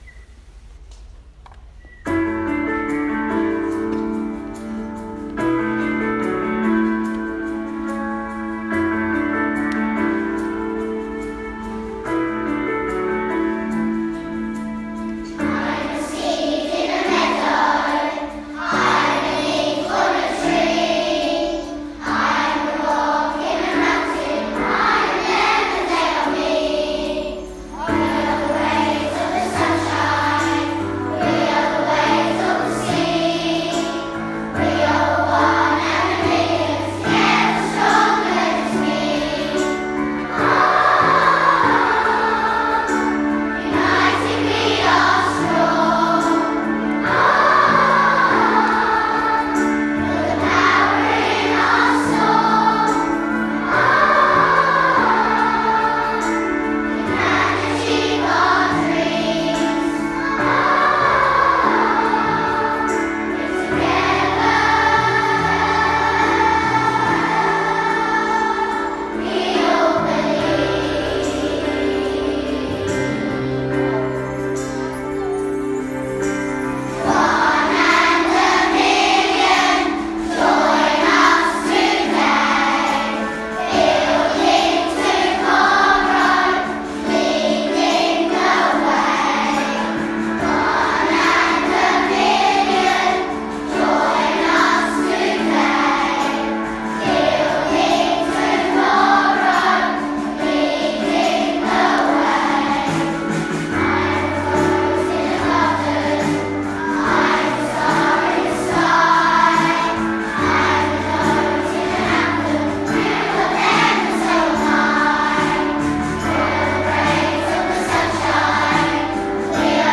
Click on the link below to listen to our whole school singing 'One in a Million.' The children sang beautifully about the importance of community and being united together.